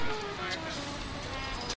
描述：在背景中可以听到小号声，因为另一辆大车滚过，用DS40录制，因为左边的麦克风神秘地停止了工作，在Wavosaur中被抢救为单声道录音